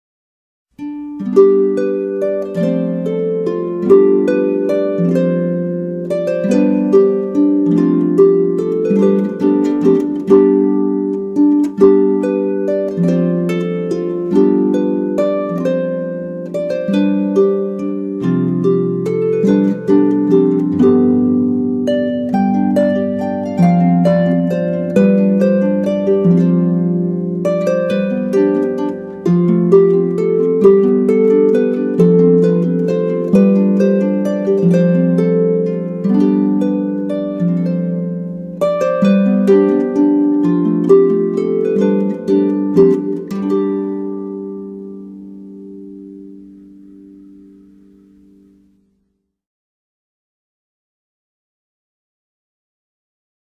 LOWER INTERMEDIATE, ALL HARPS.